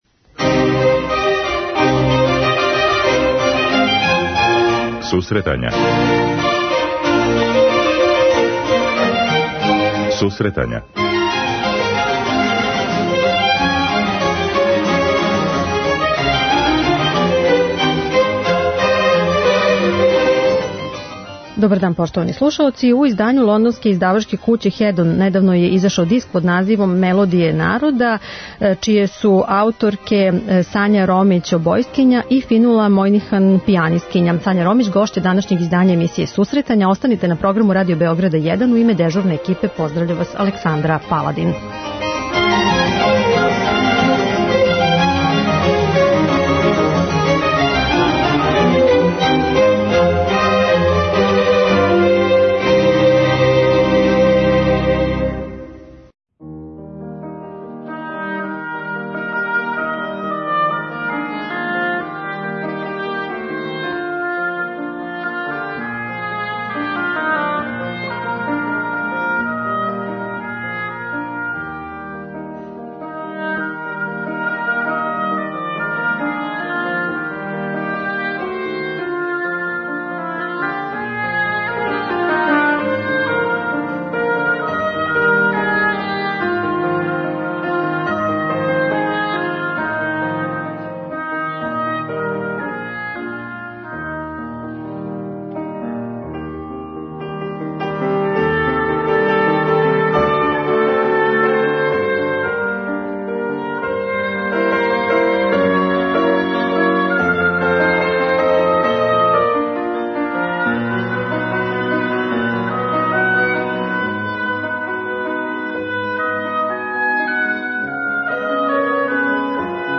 преузми : 10.73 MB Сусретања Autor: Музичка редакција Емисија за оне који воле уметничку музику.